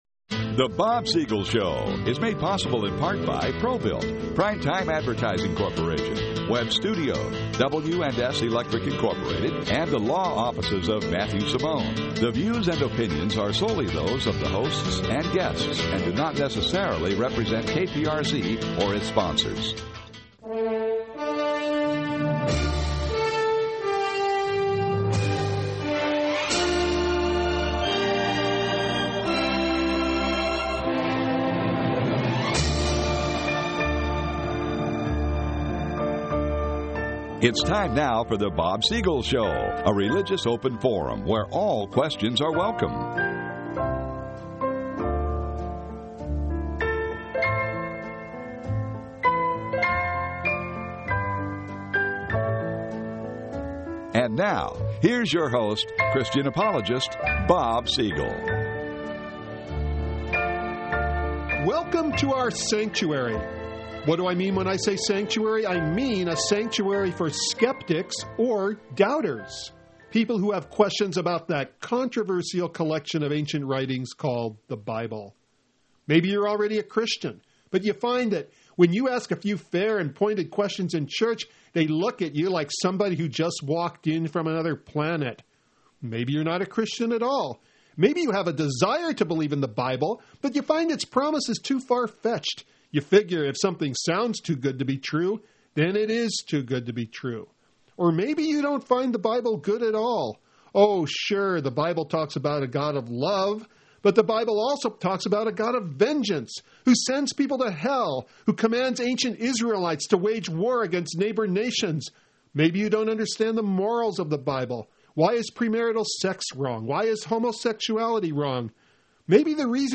-Satirical sketch: College Campus Coping Kit -One Sentence Questions, One Sentence Answers -Phone call questions -Theology discussion NOTE